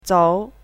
chinese-voice - 汉字语音库
zou2.mp3